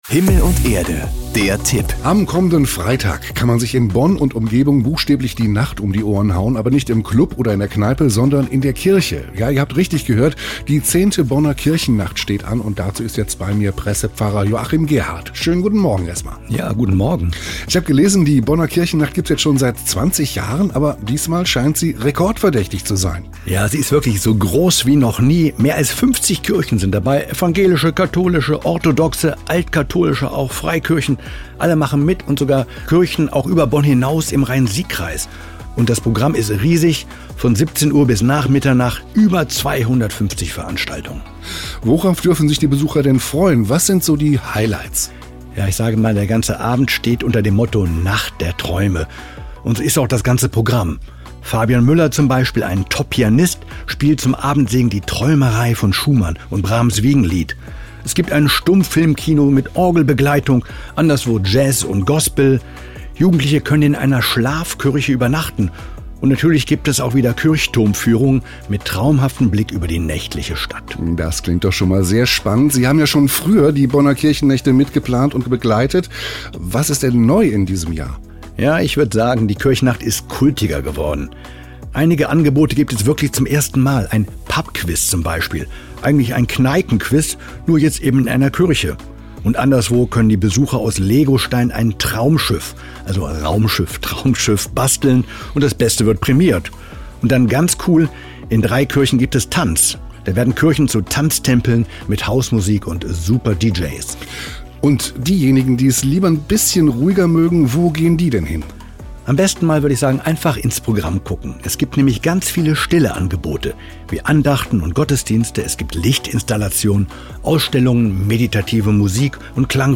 HIER aktuell Ankündigung auf den Lokalradios NRW, bei uns Radio Bonn/Rhein-Sieg.